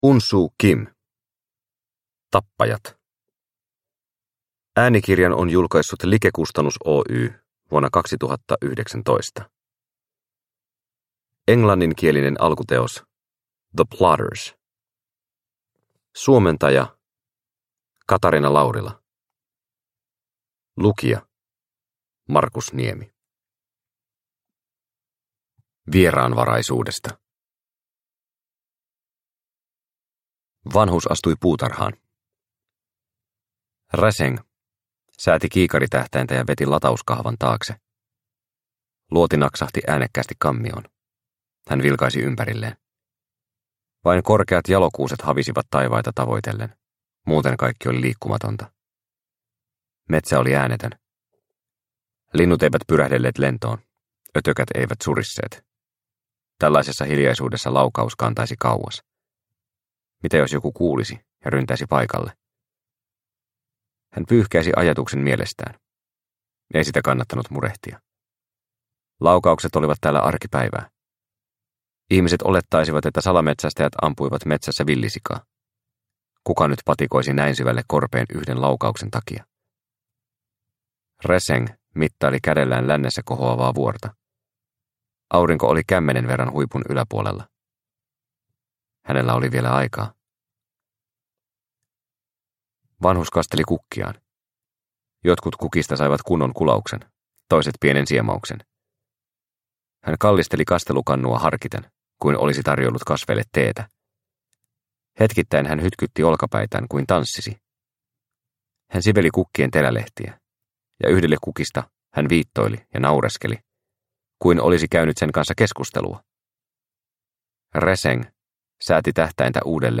Tappajat – Ljudbok – Laddas ner